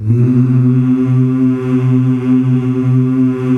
MMMMH   C.wav